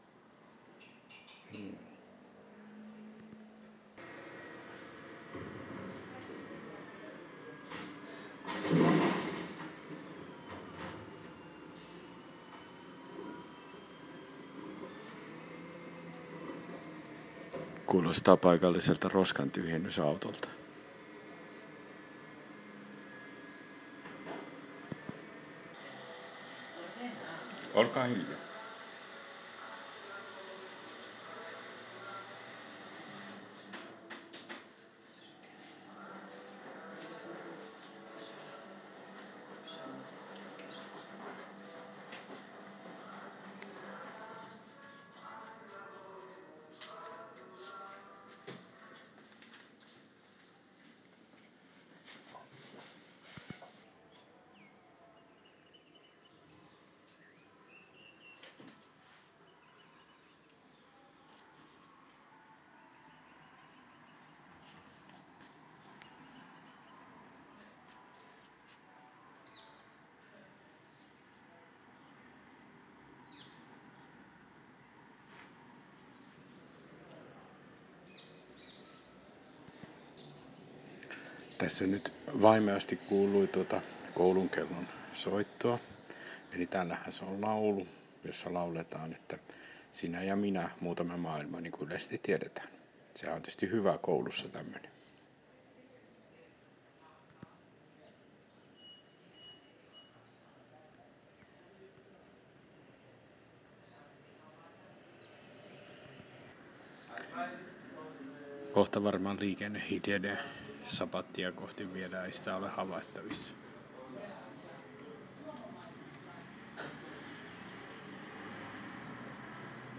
Ääniä ennen sapattia'